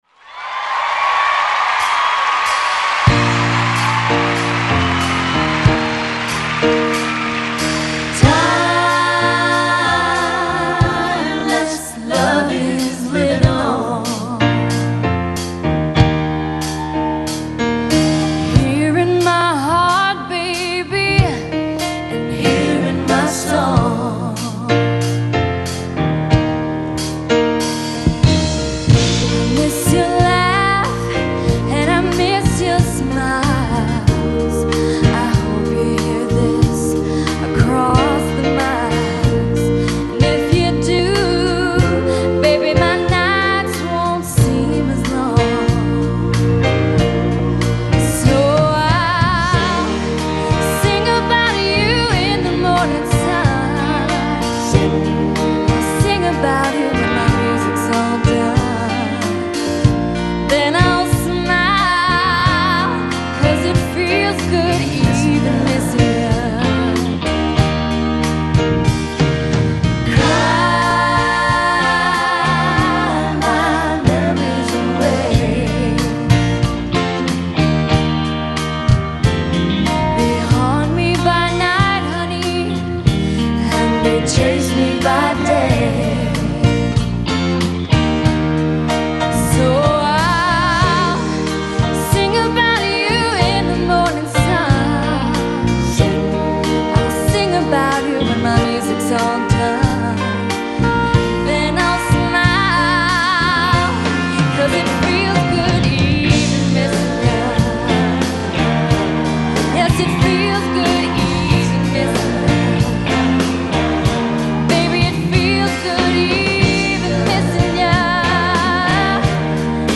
They should now  be higher quality and have the same volume.